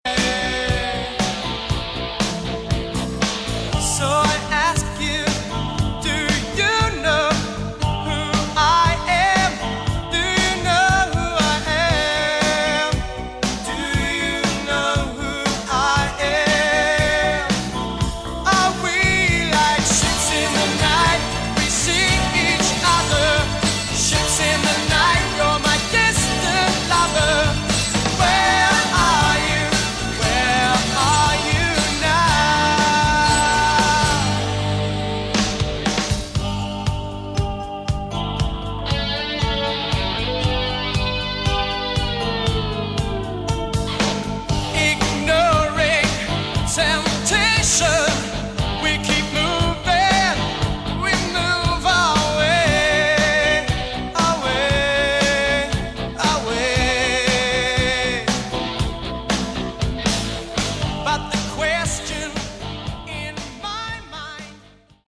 Category: AOR
vocals
bass
drums
keyboards